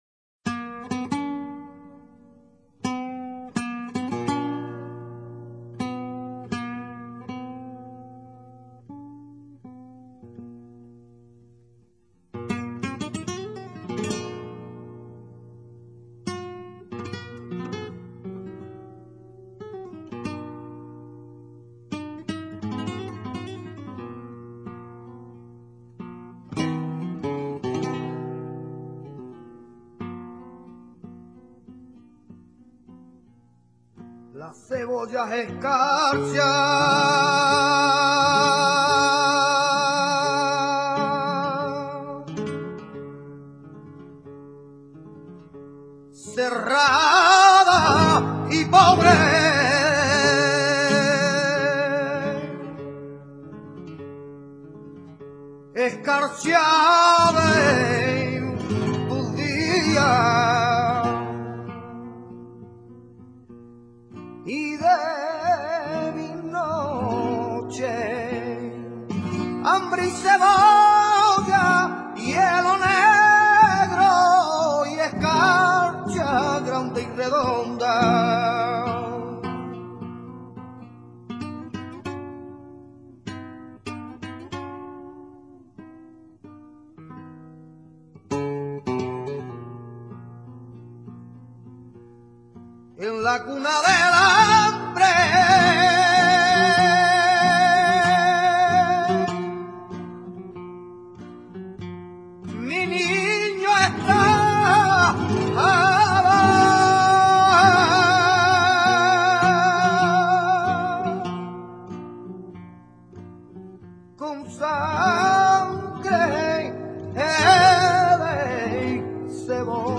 nana.mp3